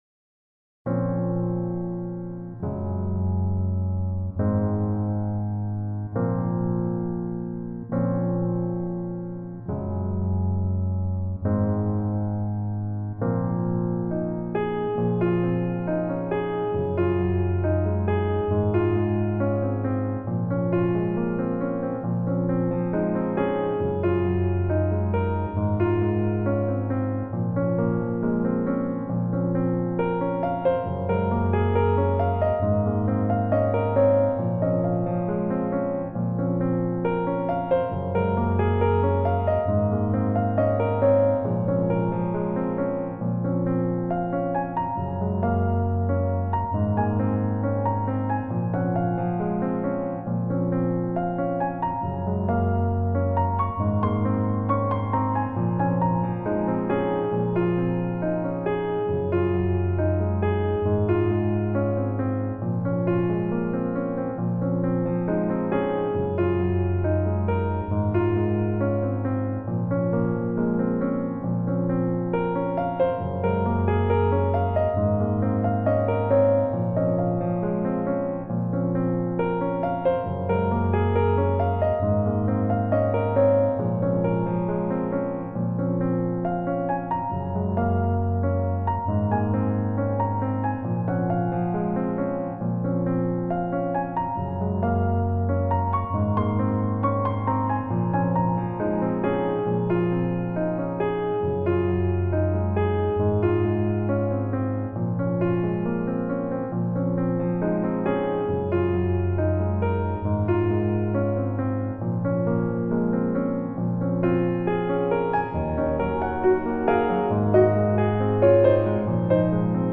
Instrumentation : Piano
Genre:  Méditation